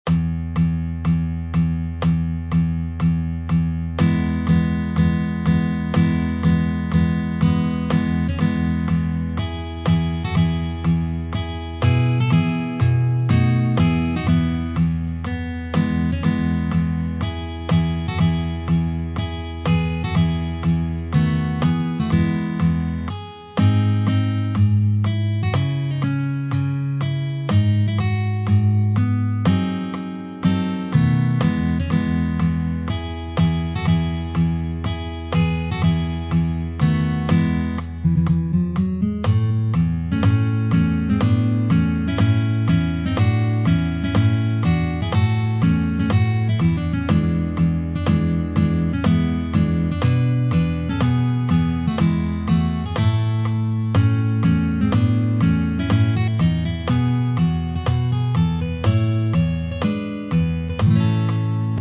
per chitarra classica
Arrangiamento molto bello è semplice per chitarra sola!